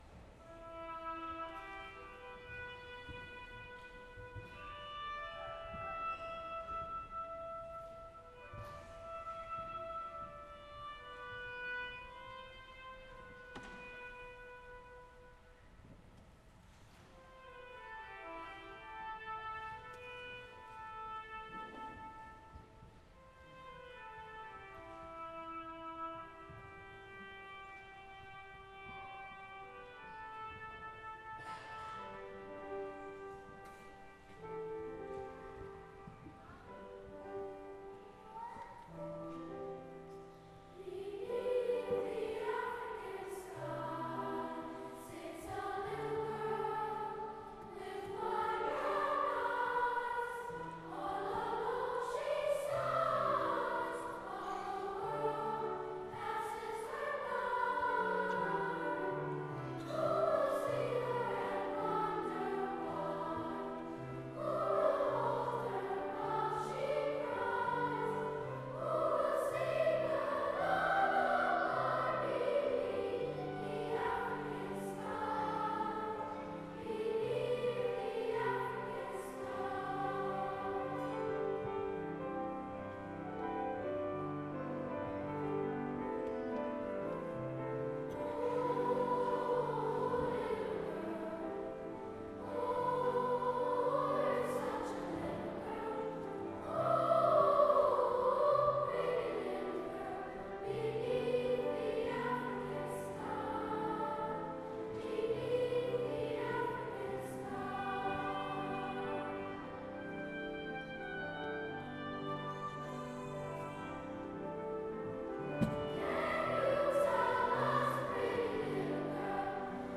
Beneath-the-African-Sky-2018-Winter-Concert.mp3